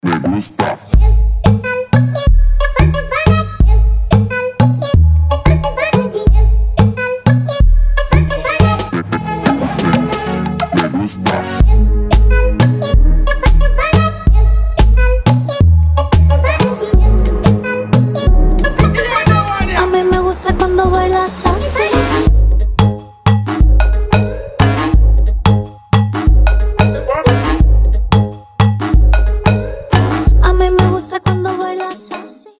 поп
мужской голос
ритмичные
женский вокал
заводные
dance
красивый женский голос
Latin Pop